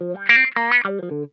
Index of /90_sSampleCDs/Zero G - Funk Guitar/Partition B/VOLUME 021